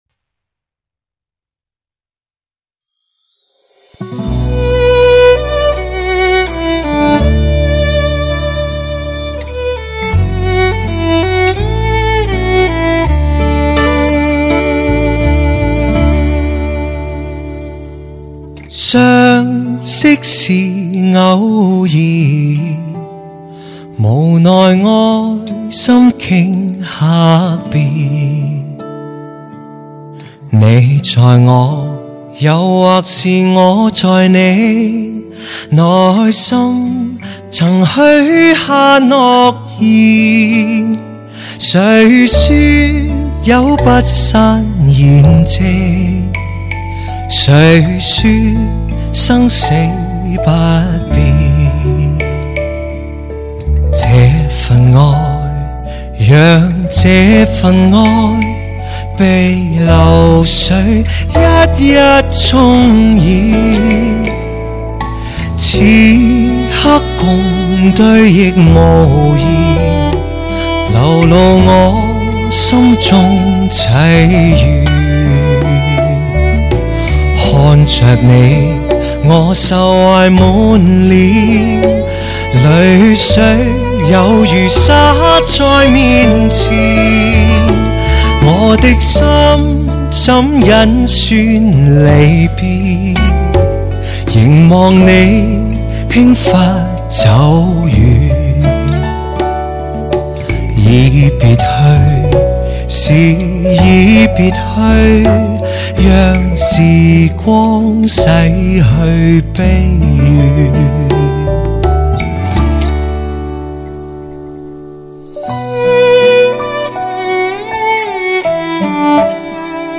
经典粤语金曲
温润如玉的歌声 历久不变
气量充沛，音域广阔，情绪饱满的唱腔，纯熟温润的歌声，温暖感性从心底倾注而出，一遍又一遍融化挑剔的耳朵和孤寂的心灵。
特有的男中音音质兼具流行音乐的忧郁抒情、古典音乐的神圣古朴和歌剧的深沉有力。